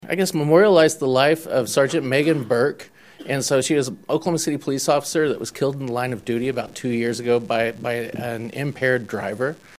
CLICK HERE to listen to commentary from Senator Michael Brooks.